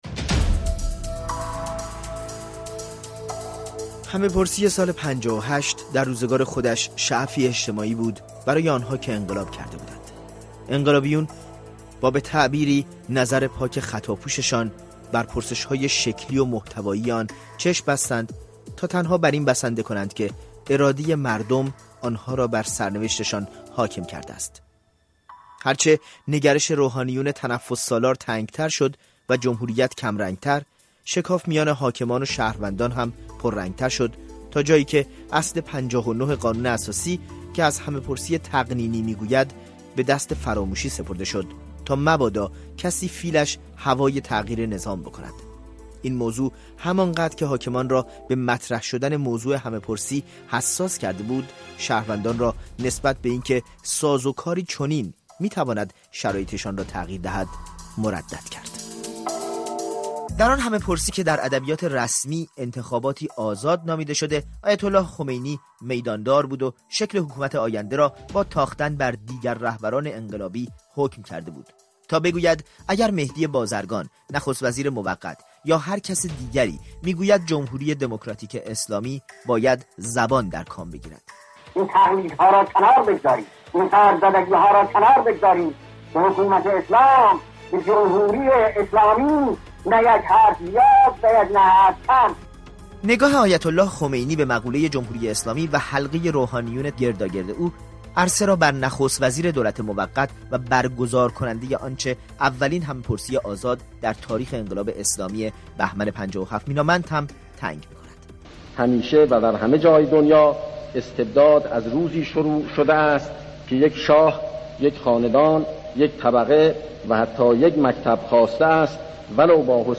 میزگردی